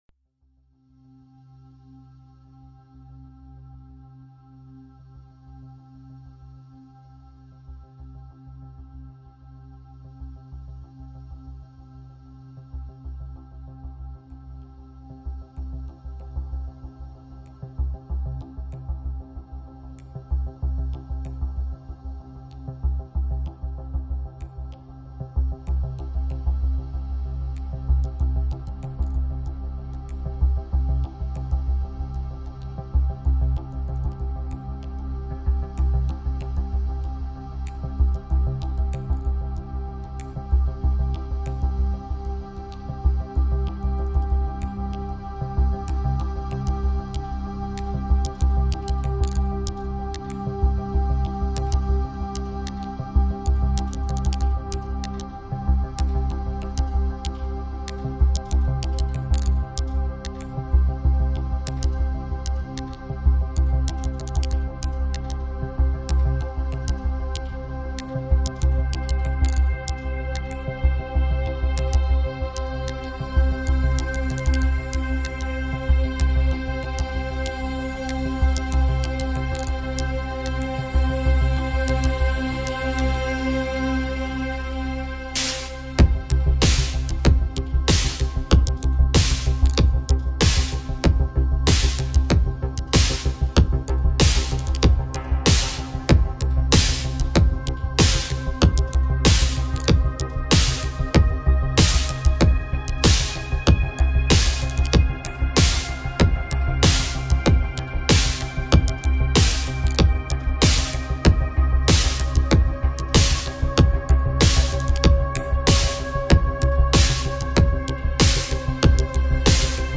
Жанр: Psychill Psybient